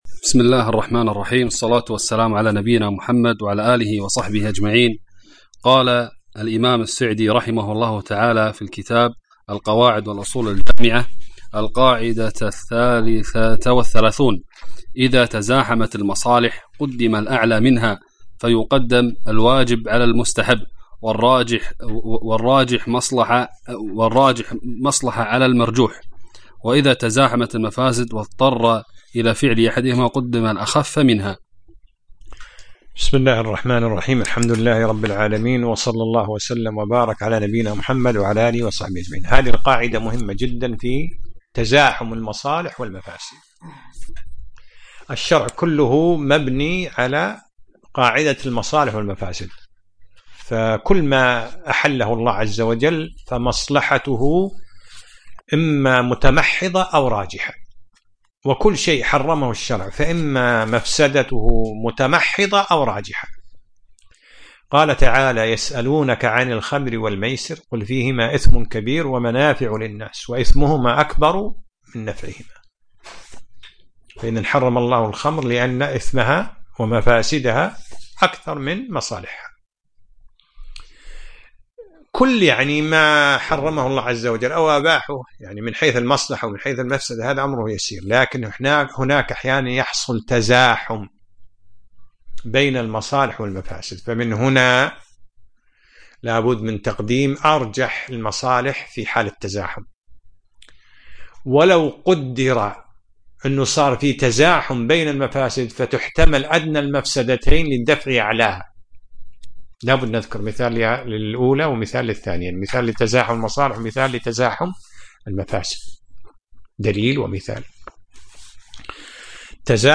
الدرس العاشر : من القاعده 33 إلى القاعده 34